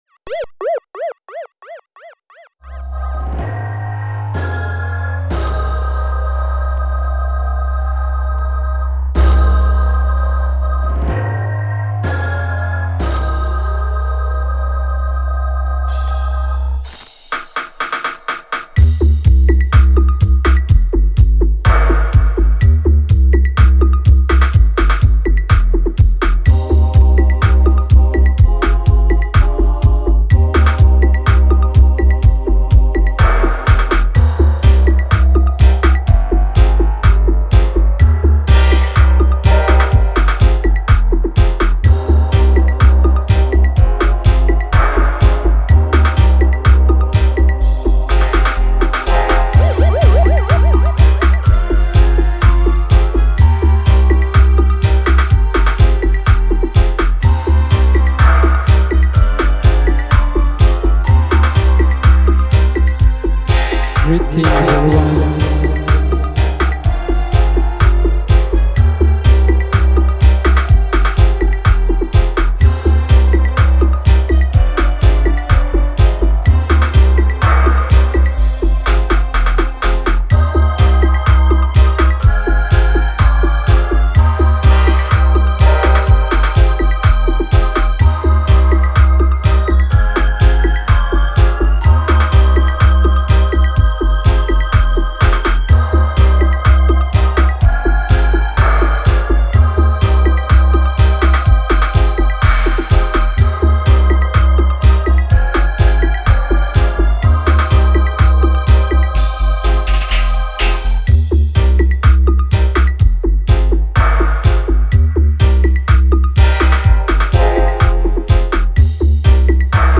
UK Roots, Steppas selection